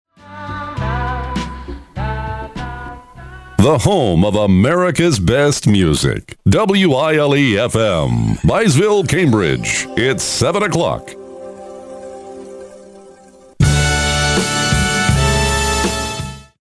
WILE-FM Top of the Hour Audio: